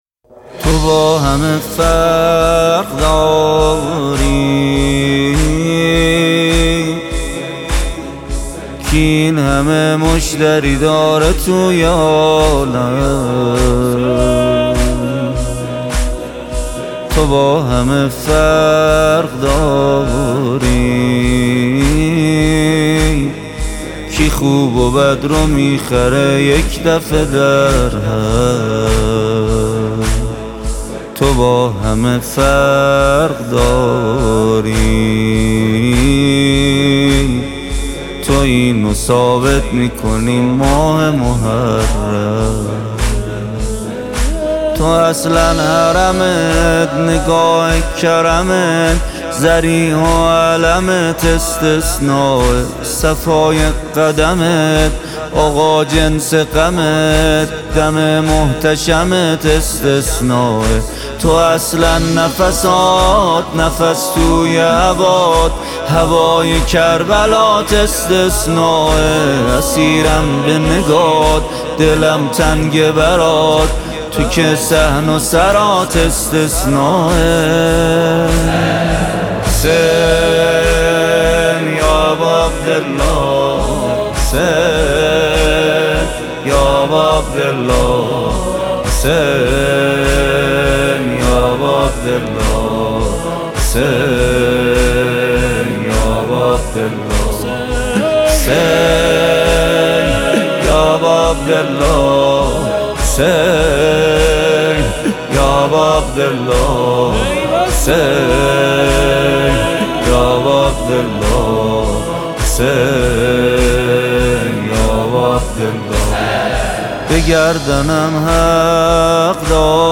پخش آنلاین نوحه